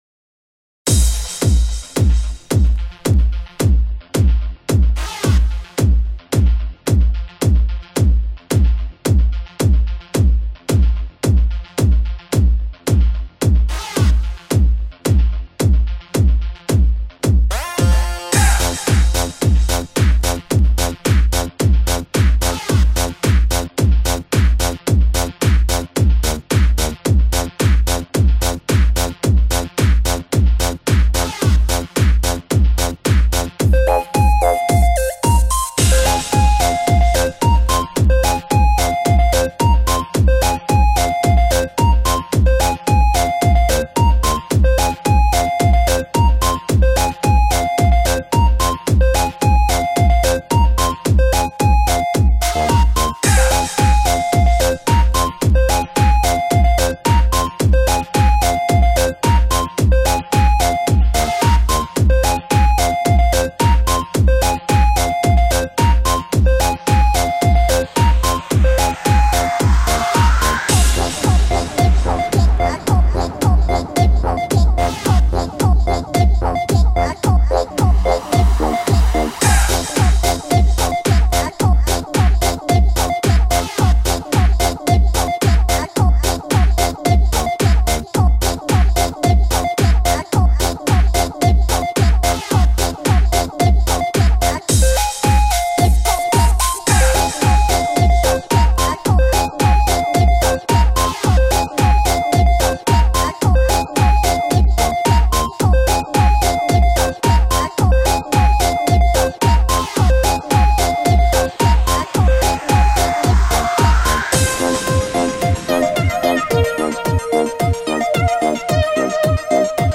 [15/4/2010]超重低音，小心音响 激动社区，陪你一起慢慢变老！